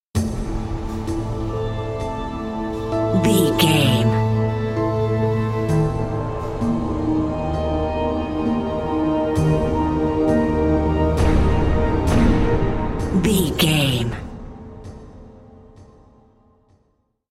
Aeolian/Minor
piano
synthesiser
drums
harp
ominous
dark
suspense
haunting
creepy